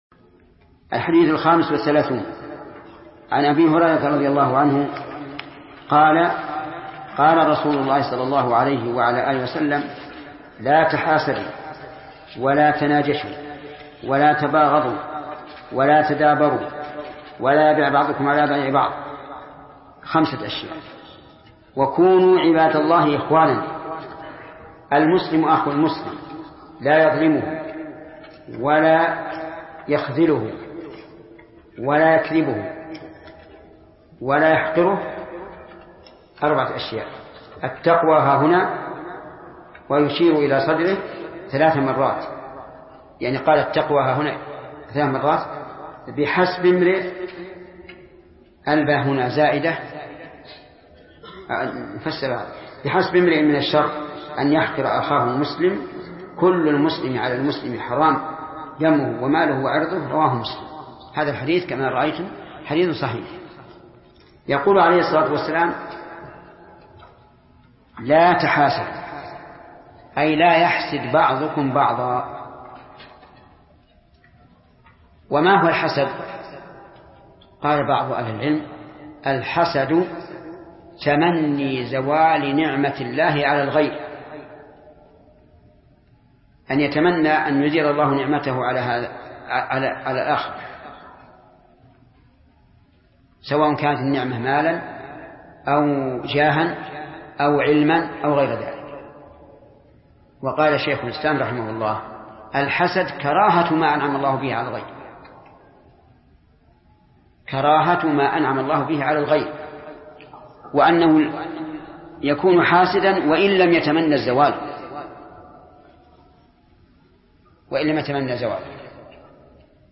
الدرس الخامس والعشرون : من قوله: الحديث الخامس والثلاثون، إلى: نهاية الحديث الخامس والثلاثون.